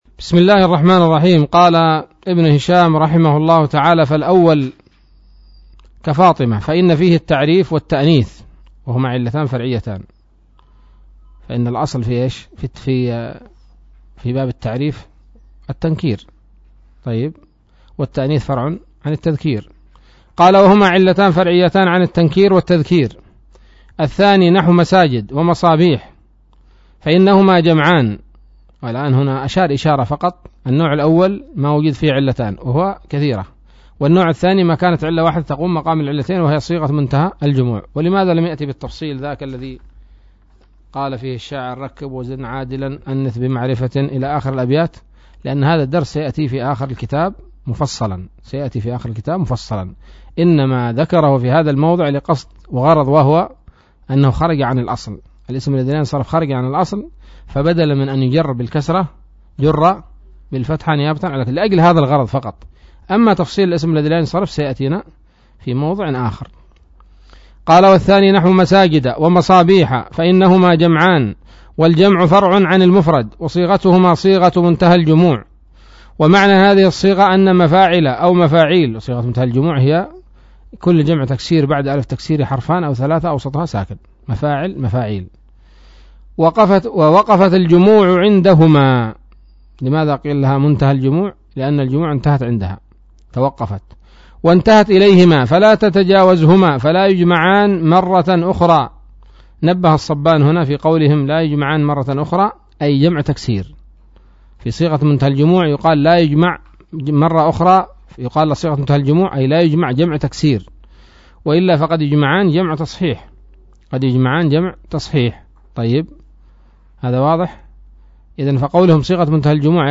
الدرس الرابع والعشرون من شرح قطر الندى وبل الصدى